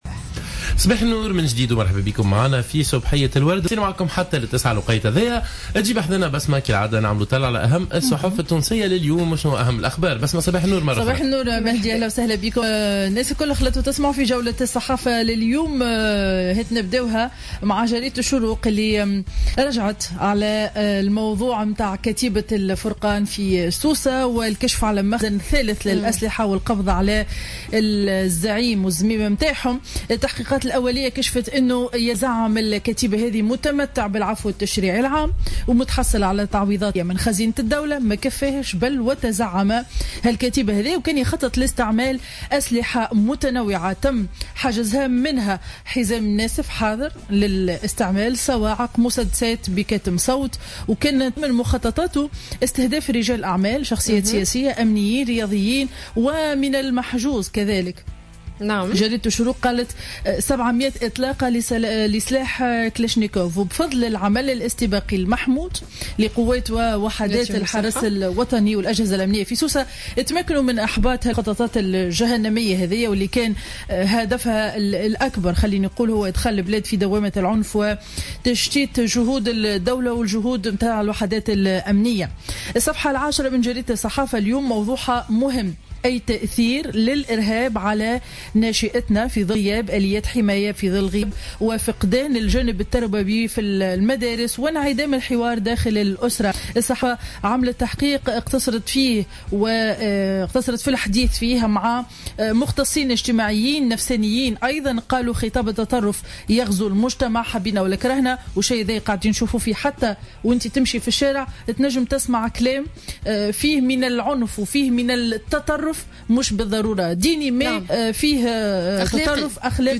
معرض الصحافة ليوم الأربعاء 02 ديسمبر 2015